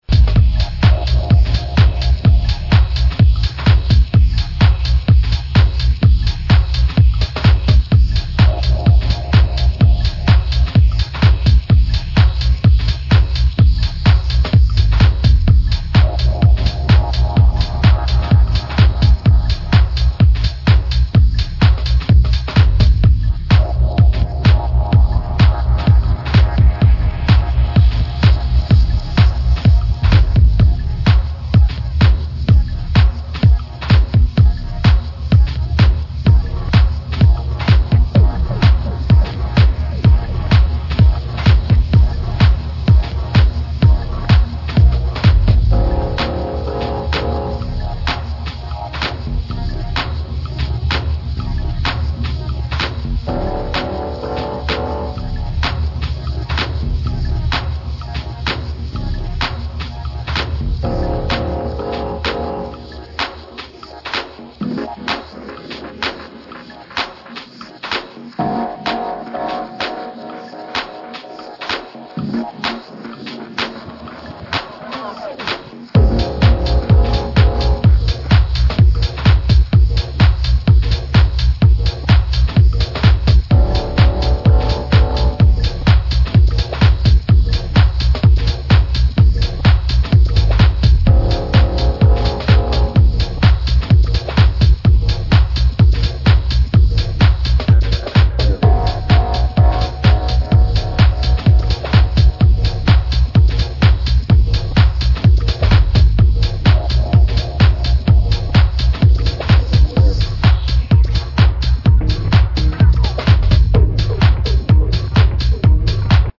deeper house grooves and hypnotic rhythms